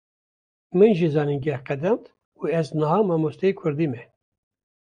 Vroulik
/zɑːniːnˈɡɛh/